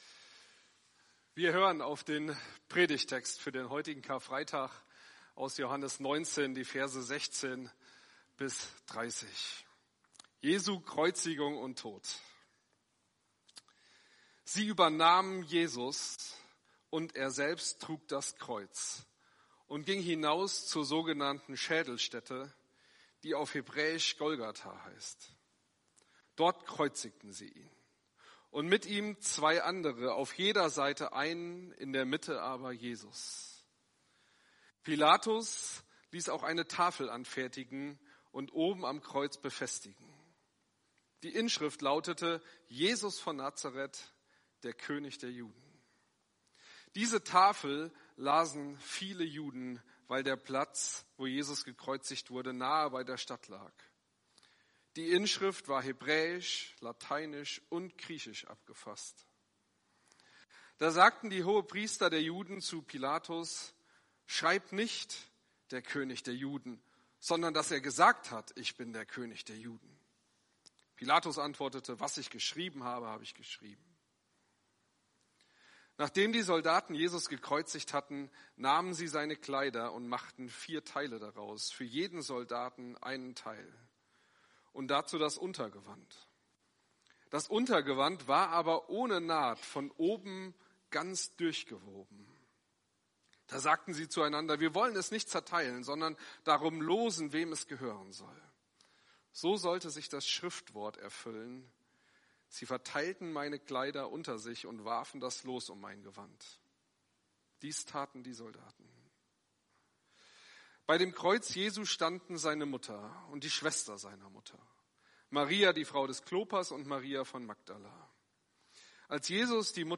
Predigt vom 28.07.2024